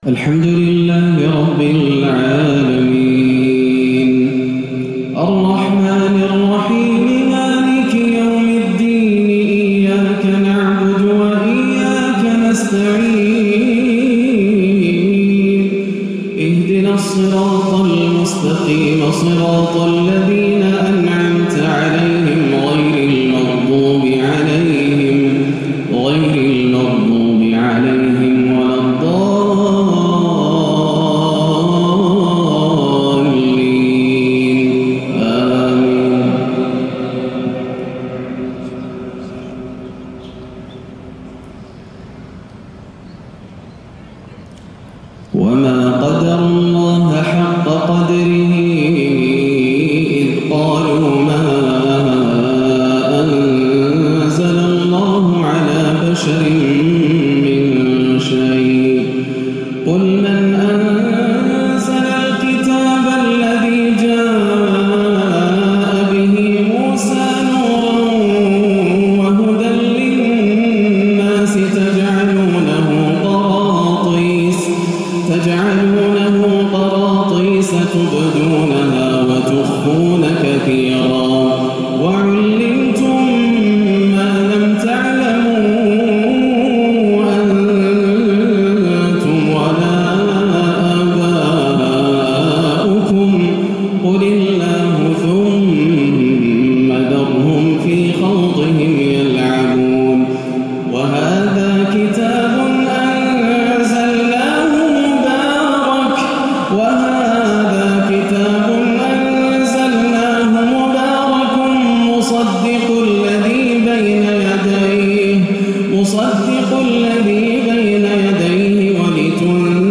﴿ ذلكم الله ربكم ﴾ آيات عظمة الله عشائية مؤثرة للشيخ ياسر الدوسري ٢٢-١ > عام 1438 > الفروض - تلاوات ياسر الدوسري